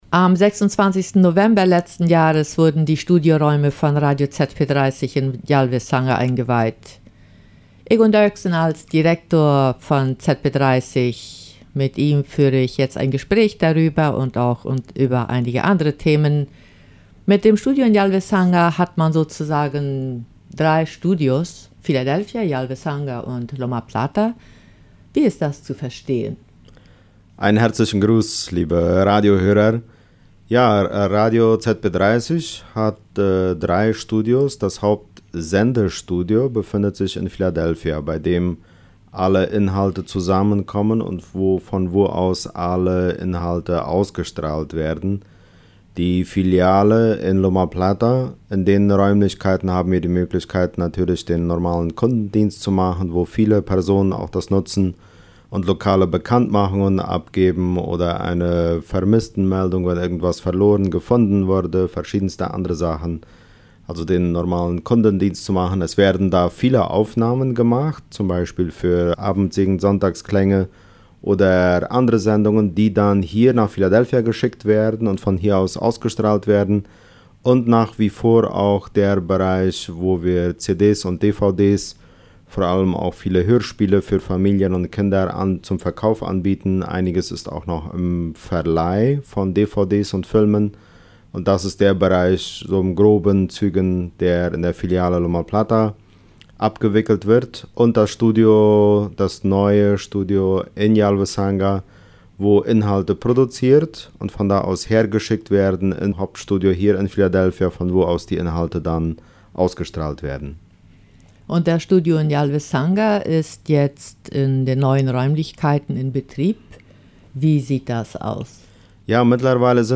ZP-30 - Interview